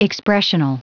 Prononciation du mot expressional en anglais (fichier audio)